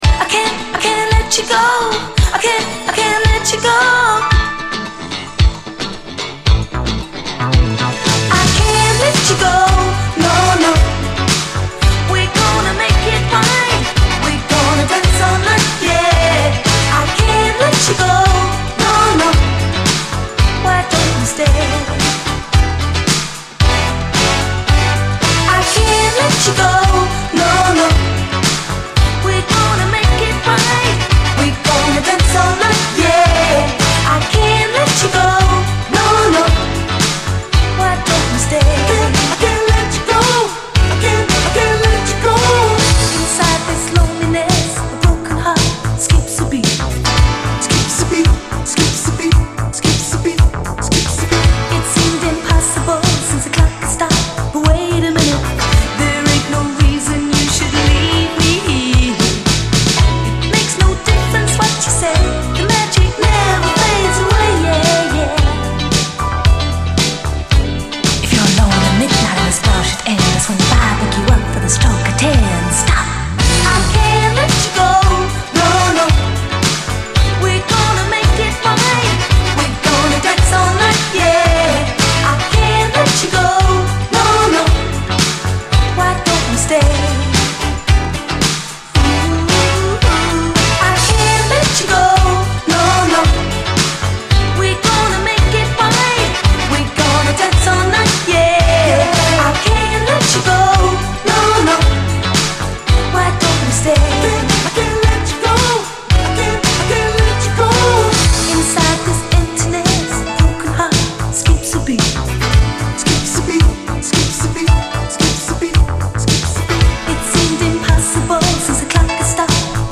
SOUL, 70's～ SOUL, DISCO
UK産80’Sキャッチー・シンセ・ブギー！